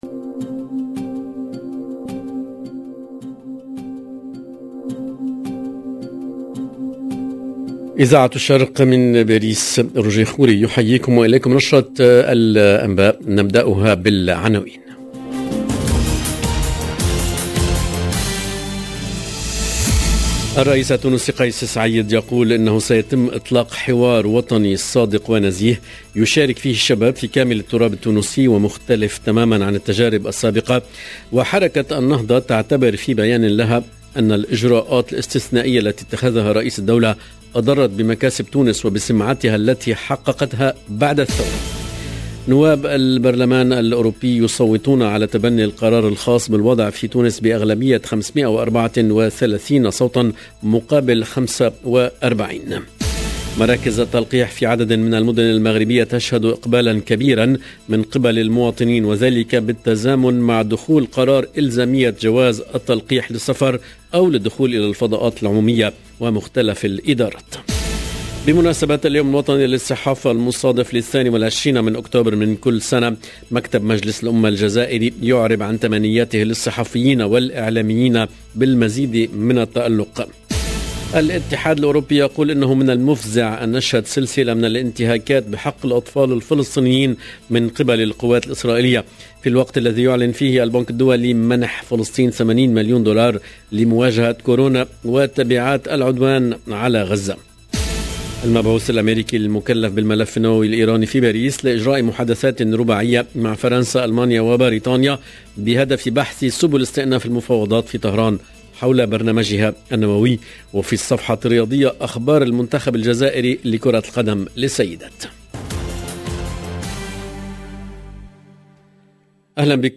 LE JOURNAL DE 14H30 EN ARABE DU 22/10/21